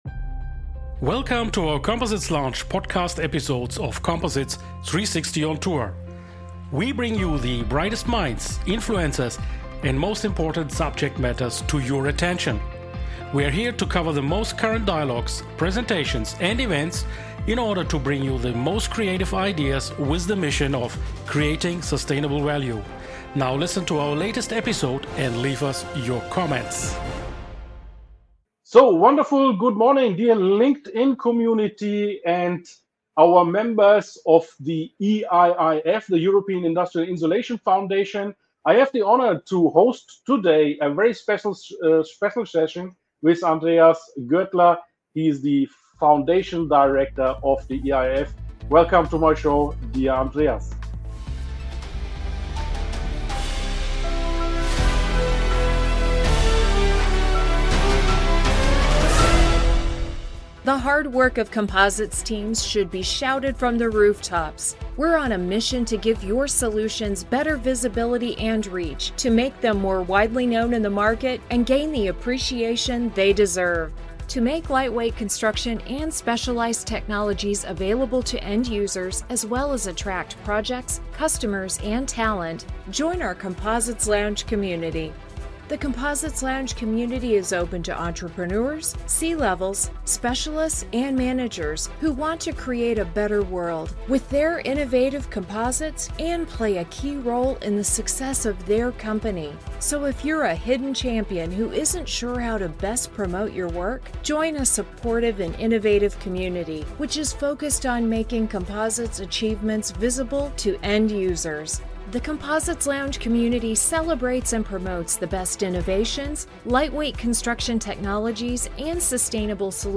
These come in two forms: Firstly, the anchoring LinkedIn Livestream (please press participate) Secondly, the corresponding LinkedIn Newsletter (please subscribe soon) For starters, I plan one online event on each quarter to maintain high quality.